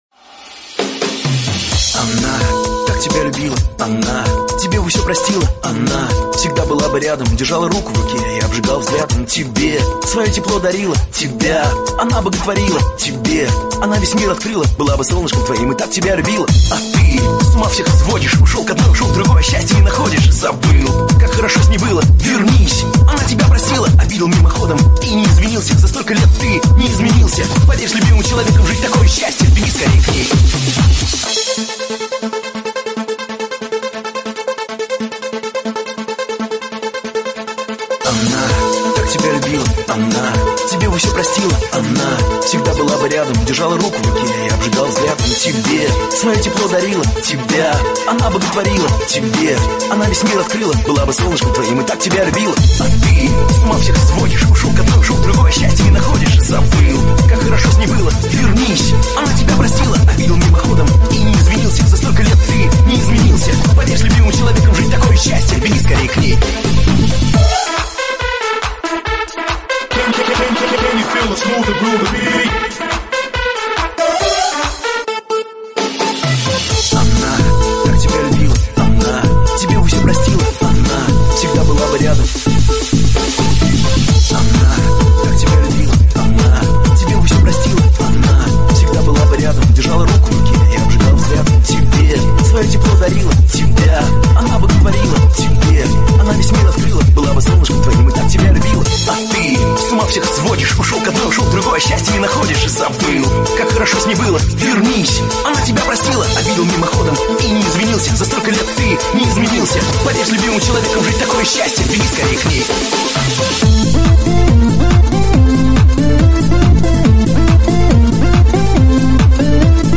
Зона обмена: Музыка | Русская ПОПса